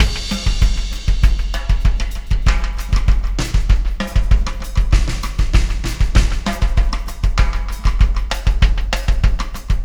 Pulsar Beat 25.wav